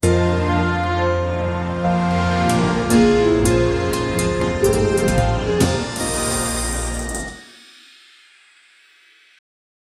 quick-transition-bykegqys.wav